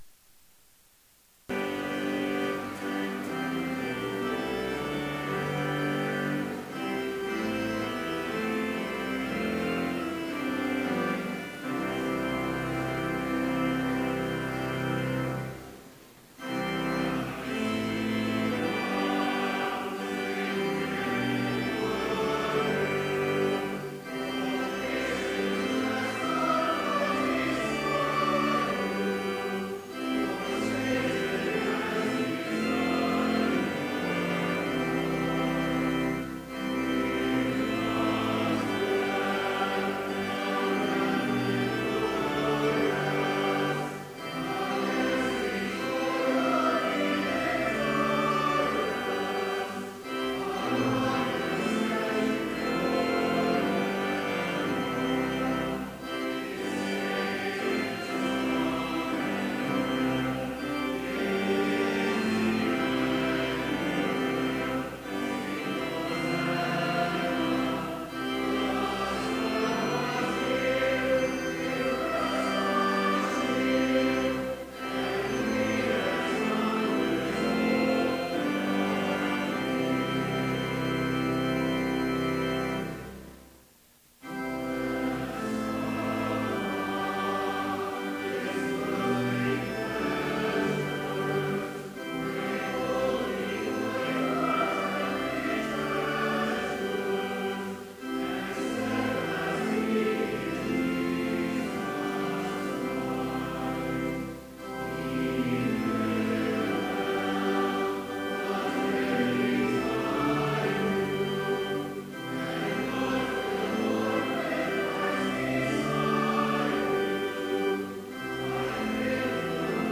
Complete service audio for Chapel - October 19, 2015
Prelude Hymn 518, vv. 1 & 2, Rise! To Arms! Reading: Ephesians 6:10-13 Devotion Prayer Hymn 518, vv. 3 & 4, Wisely fight… Blessing Postlude